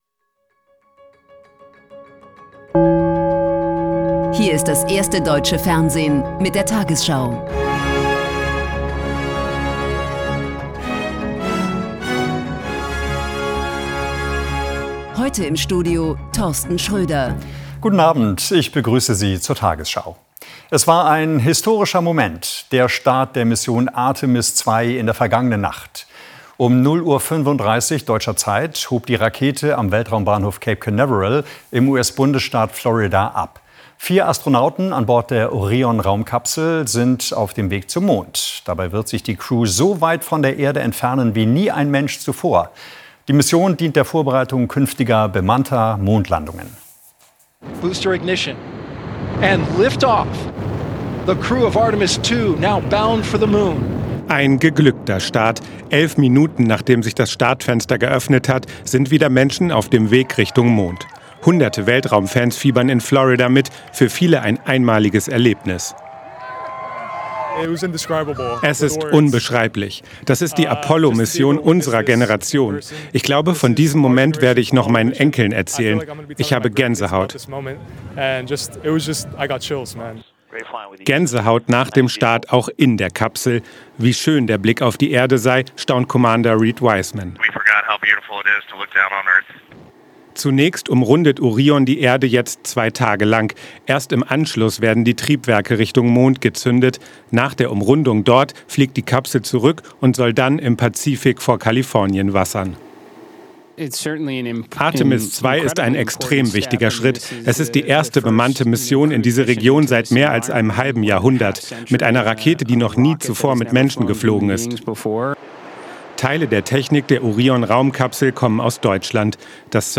tagesschau 20:00 Uhr, 02.04.2026 ~ tagesschau: Die 20 Uhr Nachrichten (Audio) Podcast